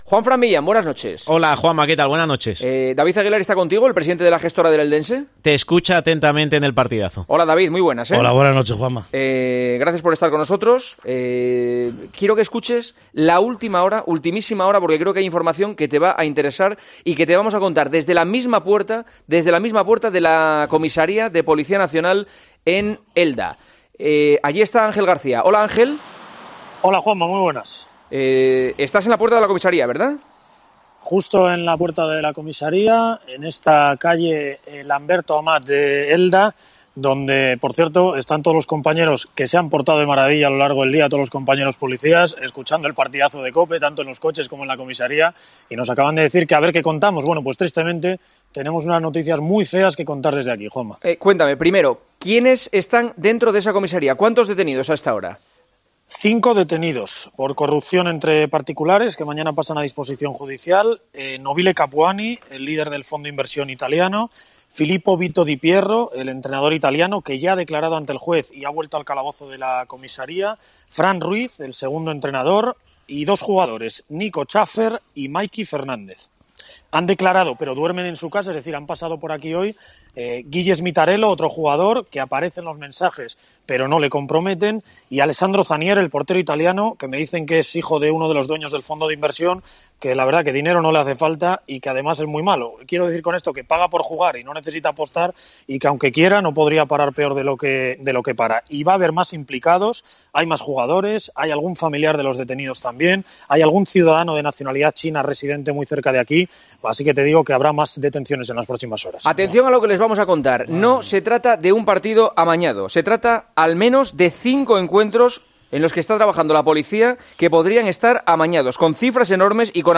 rompe a llorar al oír la información contada por El Partidazo de COPE